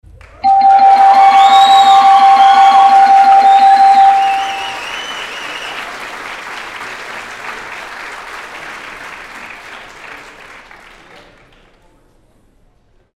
(dings; audience cheers)
sfxaudiencecheersdings.mp3